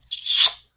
kiss love romantic sound effect free sound royalty free Memes